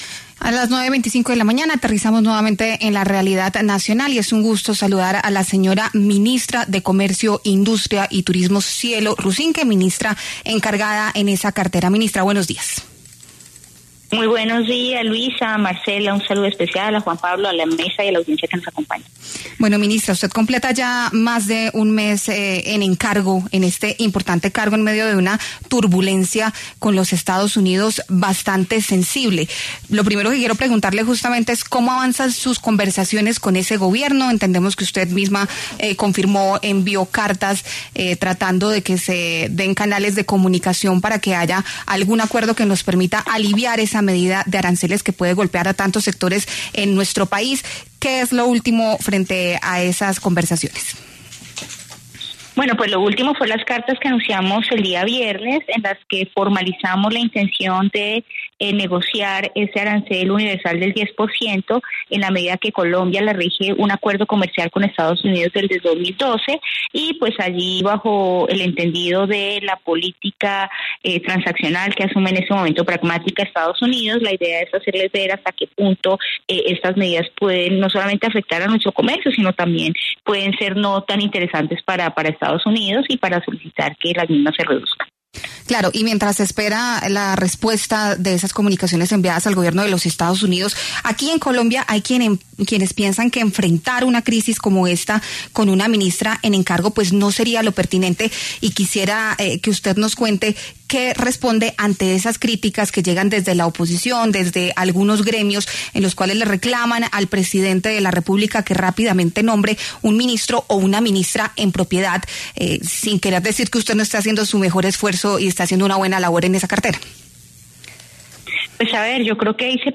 La ministra de Comercio (e), Cielo Rusinque, se pronunció en La W sobre lo que viene tras la petición que hizo el Gobierno a Estados Unidos para negociar el arancel del 10%.